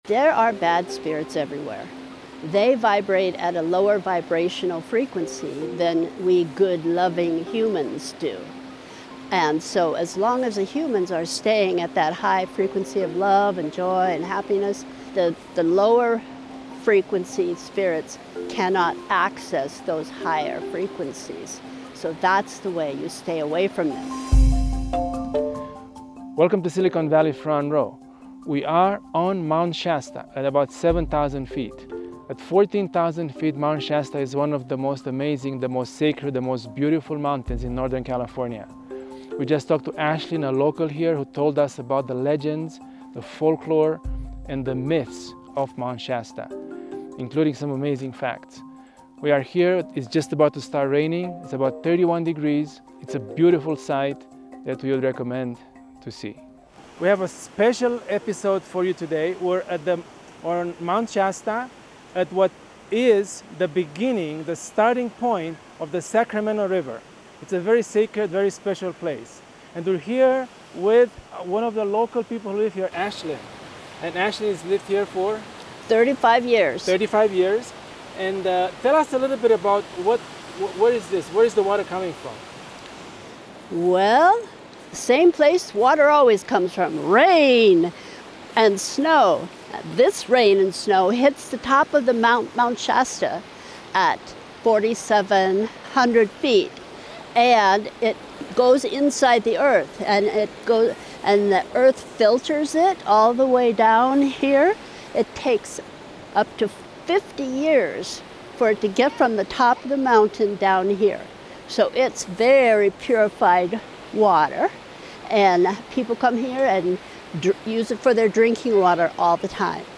We traveled to Mount Shasta, Northern California